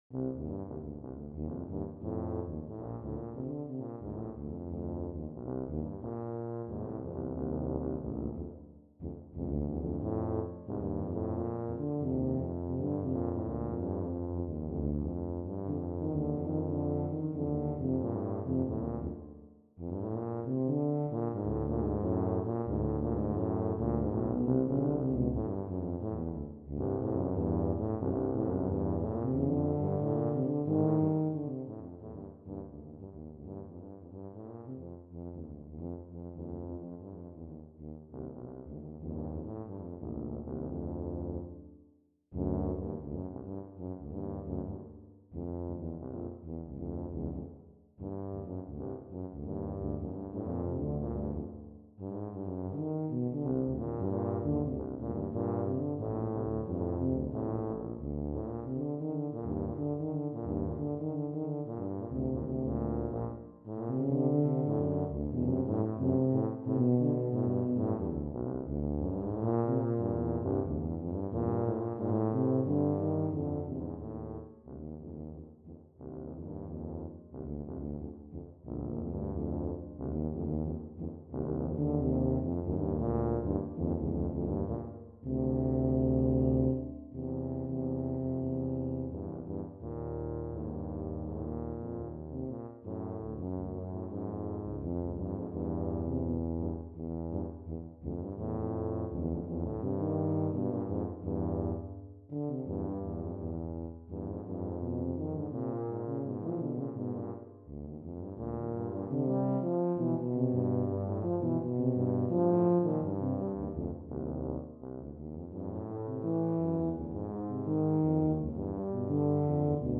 Voicing: Tuba Duet